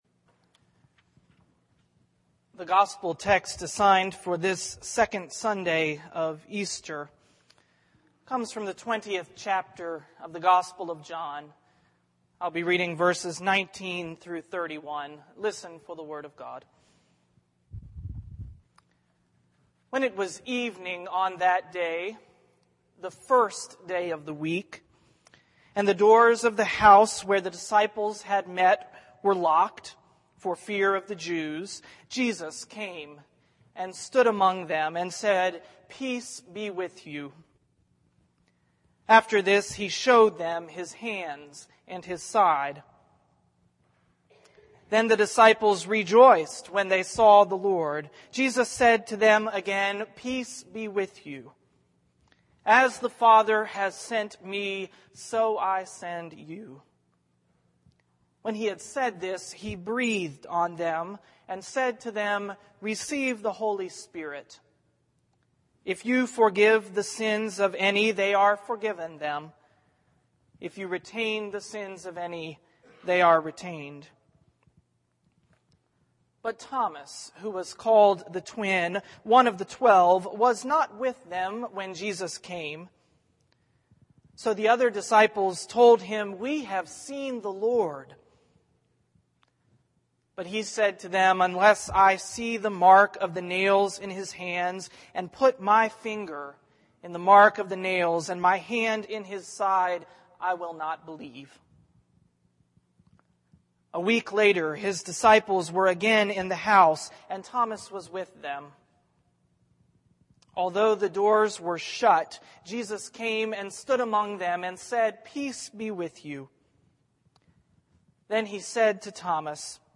worship_mar30_sermon.mp3